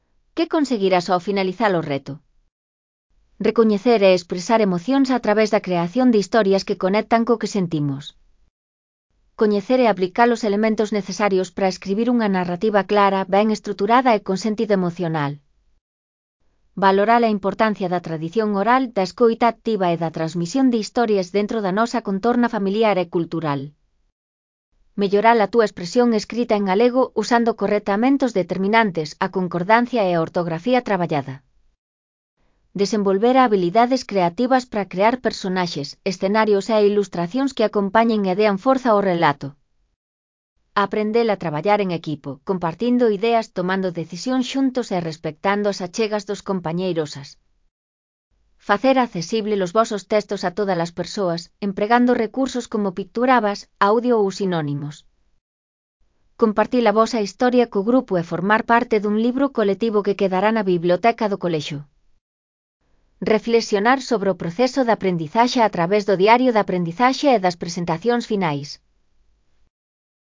Elaboración propia (proxecto cREAgal) con apoio de IA voz sintética xerada co modelo Celtia. Obxectivos finais (CC BY-NC-SA)